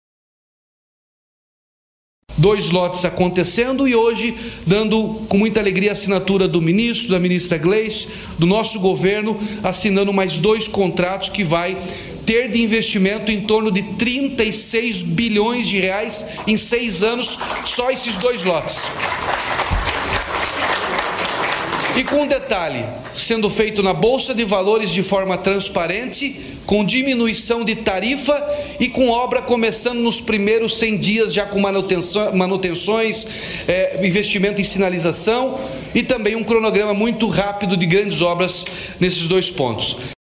O Governador do Paraná, Ratinho Junior, comentou sobre o investimento durante o anúncio das concessões, no mês de abril.